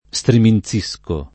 StriminZ&Sko], -sci — antiq. streminzire: streminzisco [StreminZ&Sko], -sci; e stremenzire: stremenzisco [StremenZ&Sko], -sci; e anche stremizzire: stremizzisco [StremiZZ&Sko], -sci